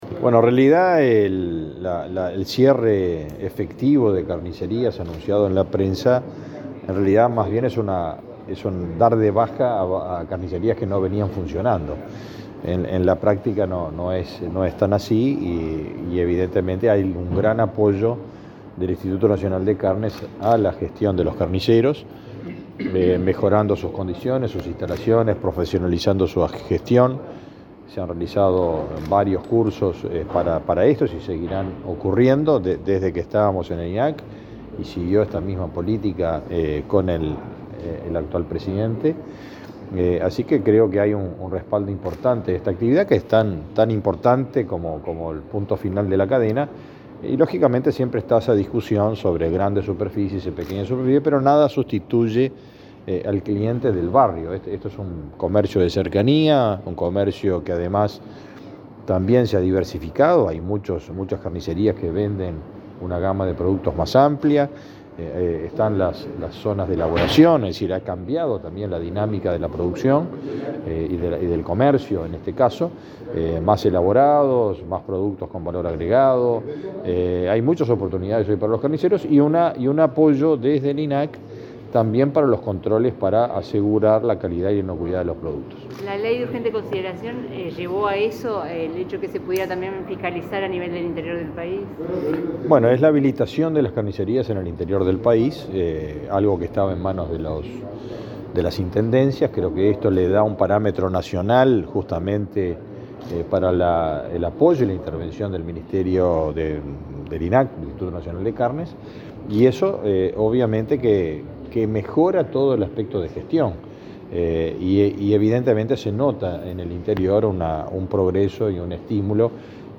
Declaraciones del ministro de Ganadería, Fernando Mattos
El ministro de Ganadería, Fernando Mattos, dialogó con la prensa luego de participar en una celebración por el Día del Carnicero.